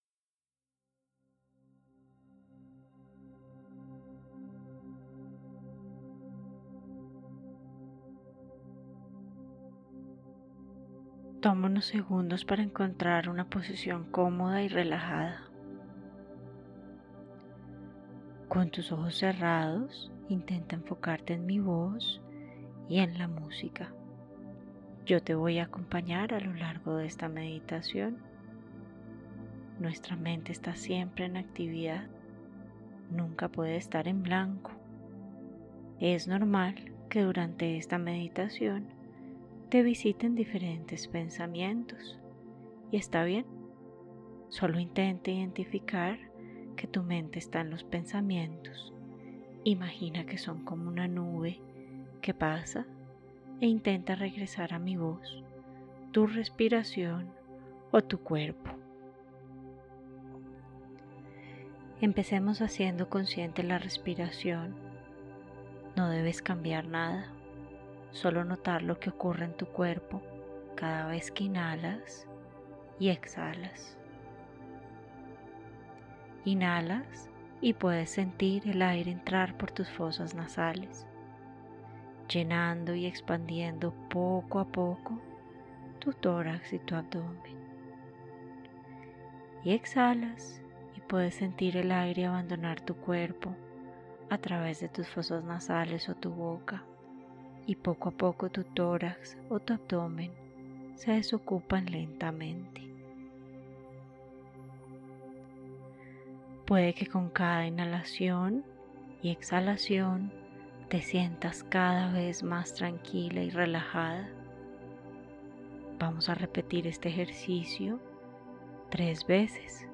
Meditación introductoria para el embarazo